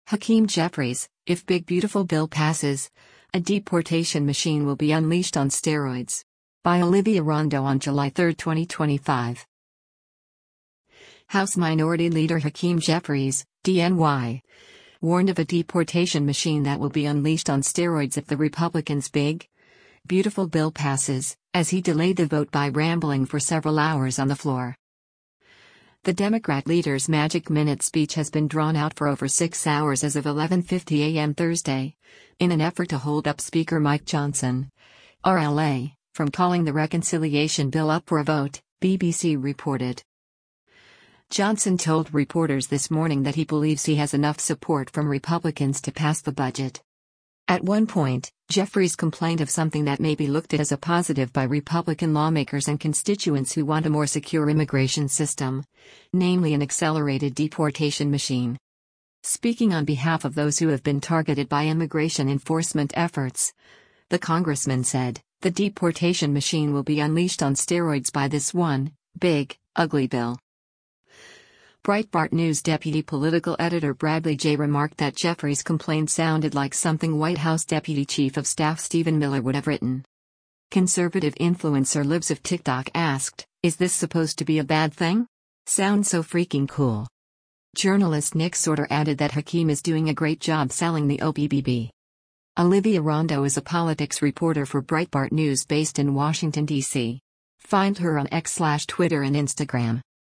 House Minority Leader Hakeem Jeffries (D-NY) warned of a “deportation machine that will be unleashed on steroids” if the Republicans’ “big, beautiful bill” passes, as he delayed the vote by rambling for several hours on the floor.